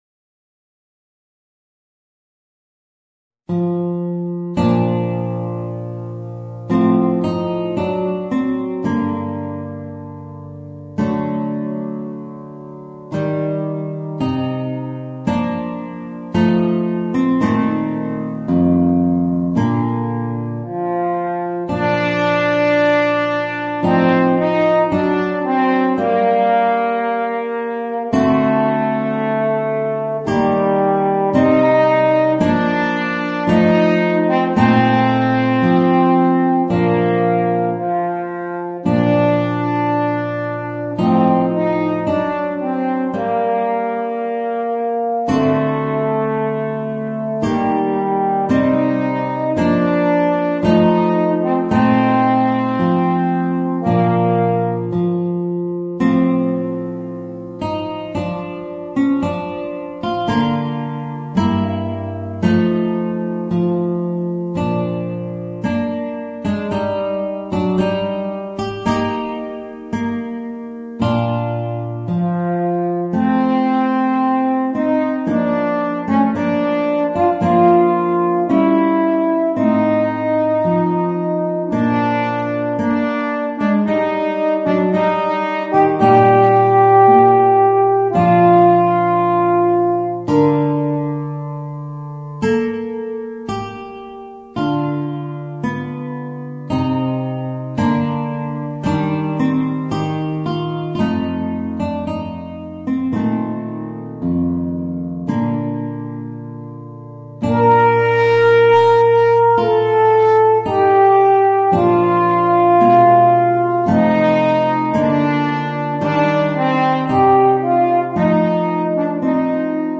Voicing: Horn and Guitar